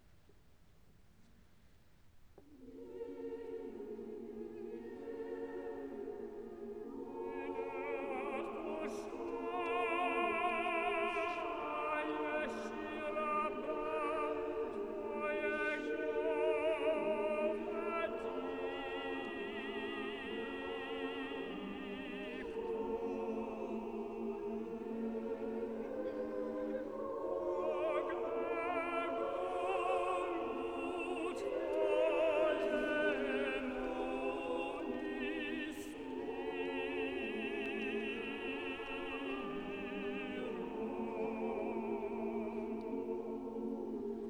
Extracts from live recordings
tenor